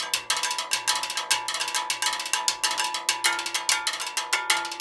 100STEELW1.wav